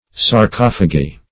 Search Result for " sarcophagy" : The Collaborative International Dictionary of English v.0.48: Sarcophagy \Sar*coph"a*gy\, n. [Gr. sarkofagi`a. See Sarcophagus .]